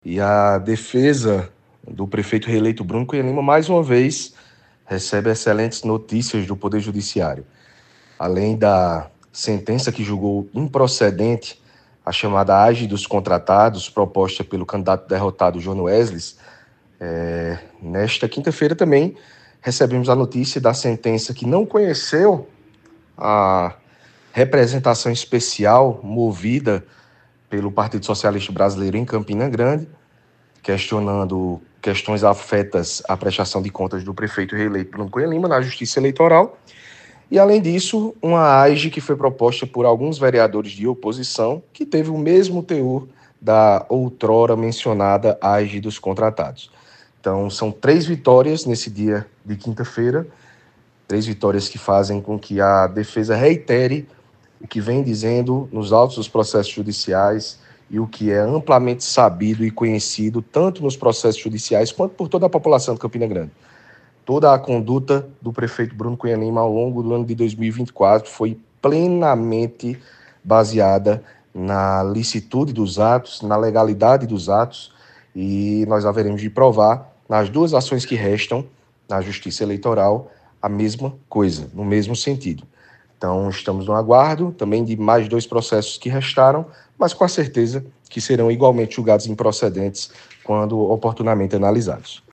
foi registrado pelo programa Correio Debate, da 98 FM, de João Pessoa, nesta sexta-feira (20/12).